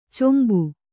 ♪ combu